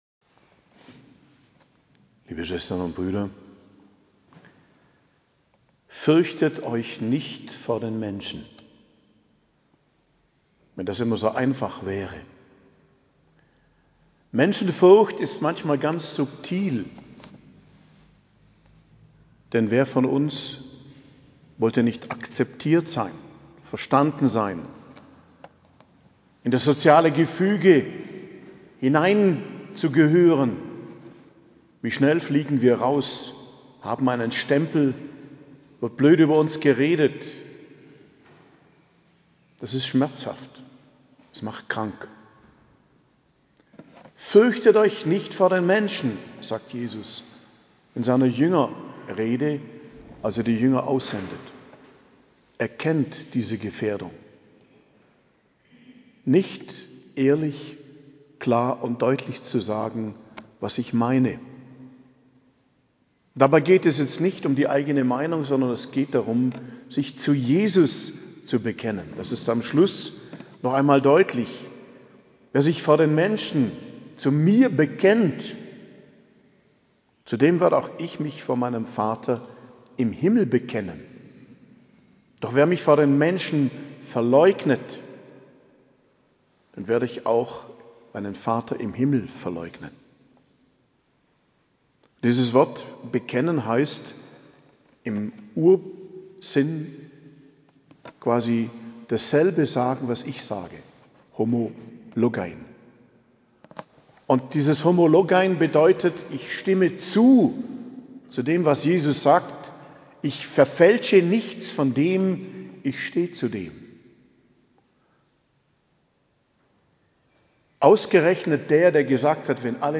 Predigt zum 12. Sonntag i.J., 25.06.2023